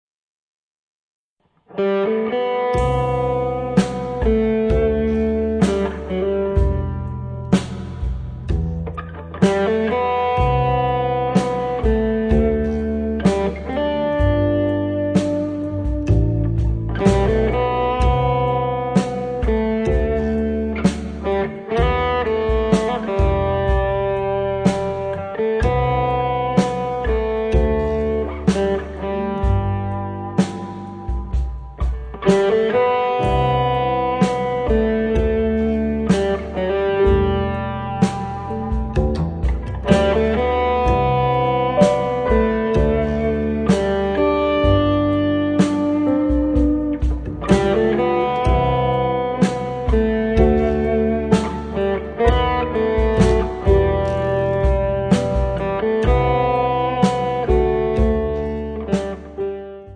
tromba, computer
alto sax, soprano
trombone
chitarra
pianoforte
contrabbasso, effetti, contrabbasso preparato
batteria e batteria elettronica